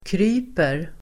Uttal: [kr'y:per]